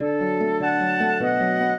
flute-harp
minuet8-5.wav